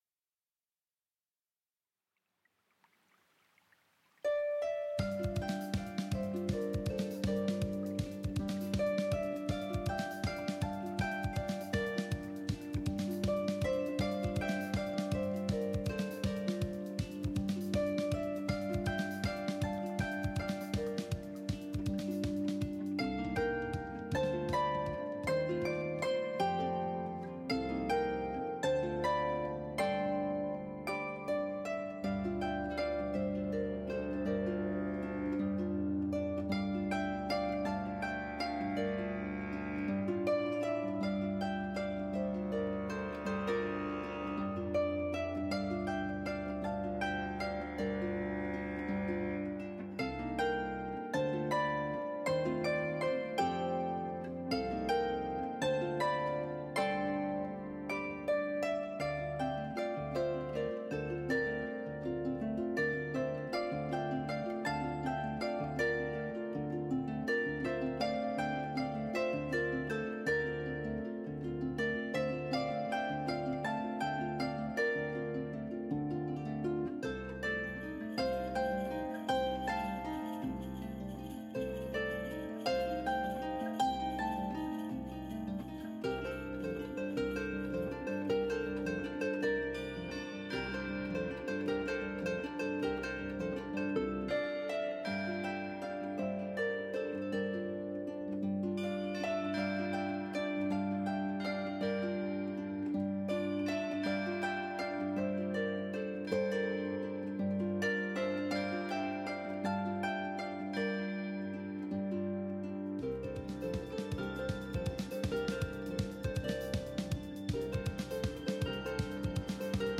original lever harp solos